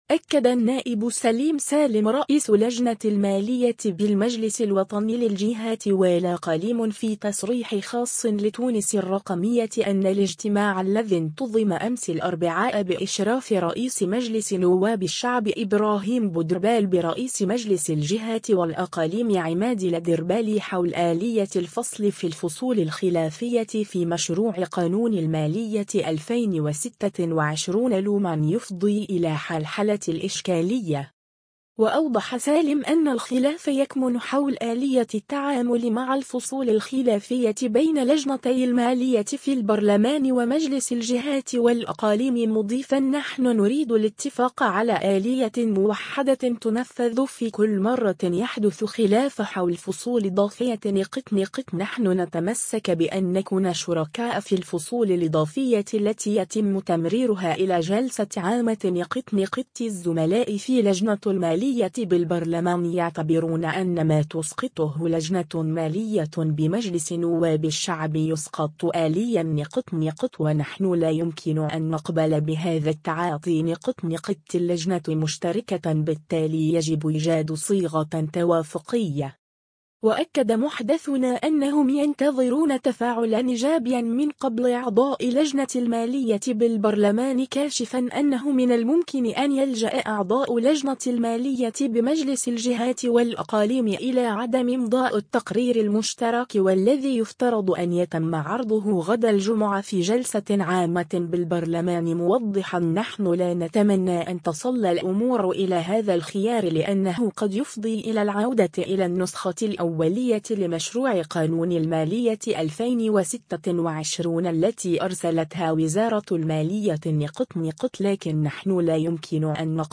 أكد النائب سليم سالم رئيس لجنة المالية بالمجلس الوطني للجهات والاقاليم في تصريح خاص لـ” تونس الرقمية” أن الاجتماع الذي انتظم أمس الأربعاء بإشراف رئيس مجلس نواب الشعب ابراهيم بودربالة برئيس مجلس الجهات والأقاليم عماد الدربالي حول آلية الفصل في الفصول الخلافية في مشروع قانون المالية 2026 لم يفضي الى حلحلة الإشكالية.